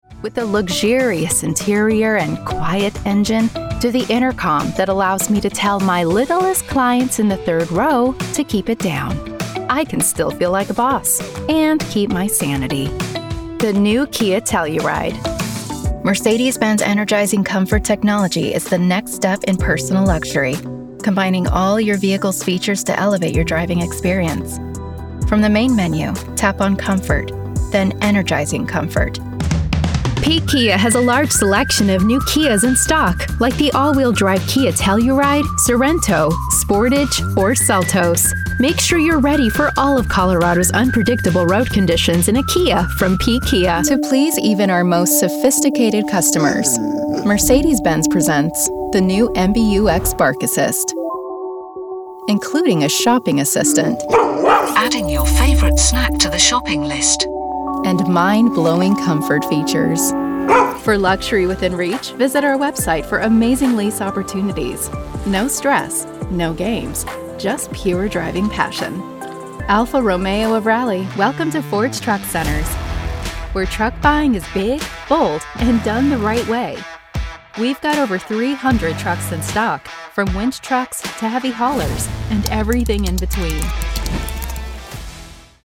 Voiceover Artist - Heartfelt Storytelling
Automotive Reel
Neutral English